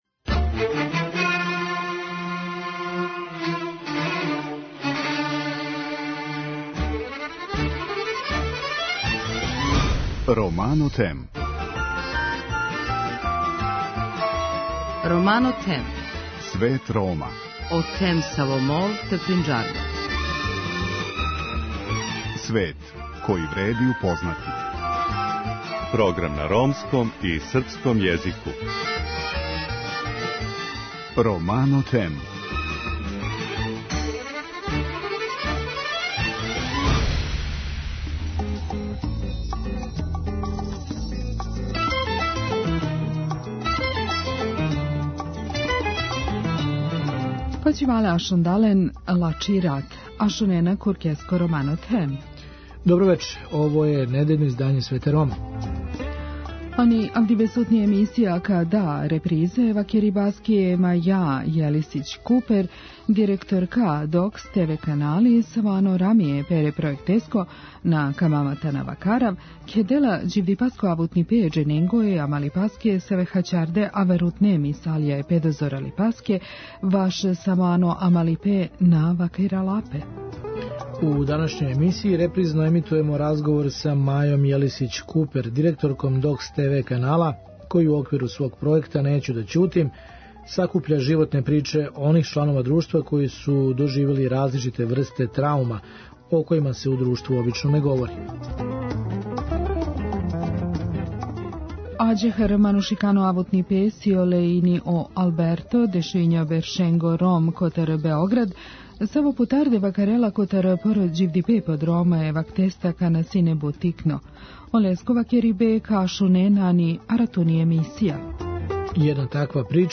За крај смо припремили дужу верзију репортаже о казанџијском занату којим се традиционално баве Роми.